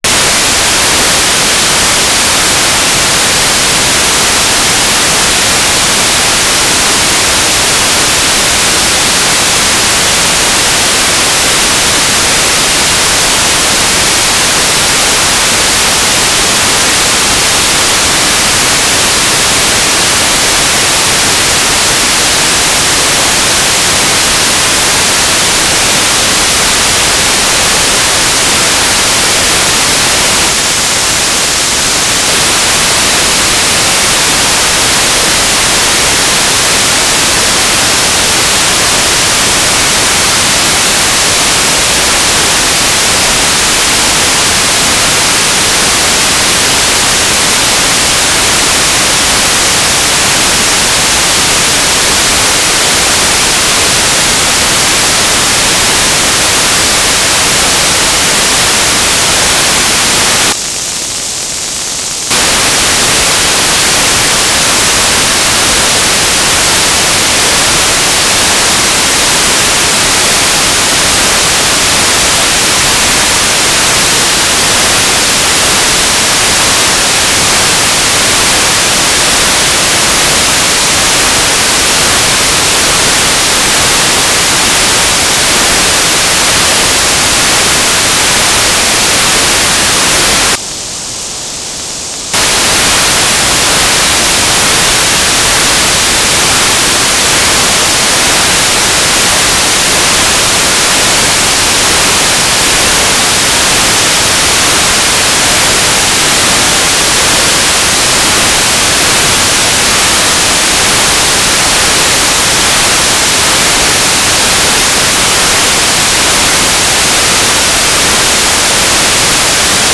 "transmitter_description": "Mode U - GMSK2k4 USP",
"transmitter_mode": "GMSK USP",